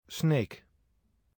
Sneek (Dutch pronunciation: [sneːk]
464_Sneek.ogg.mp3